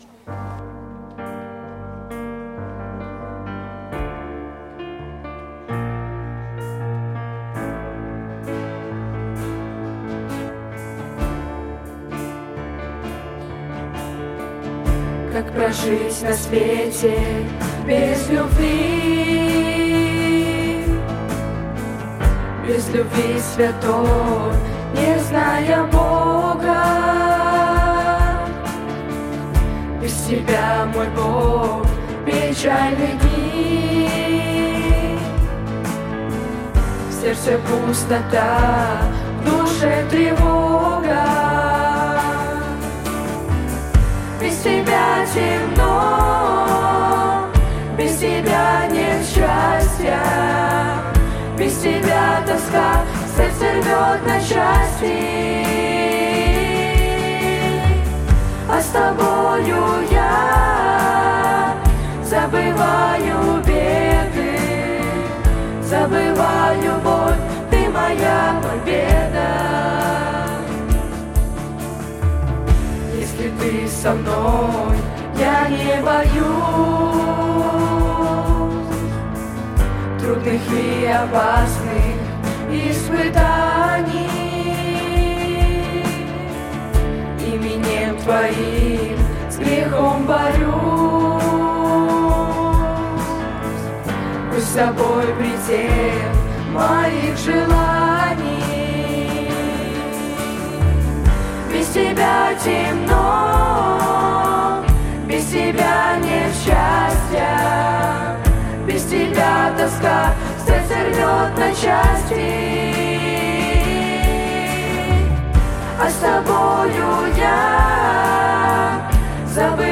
Музыкальная группа
вакал
гітара
клавішы
скрыпка
бас-гітара
ударныя
саксафон